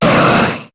Cri de Latias dans Pokémon HOME.